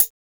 SIN CHH 2.wav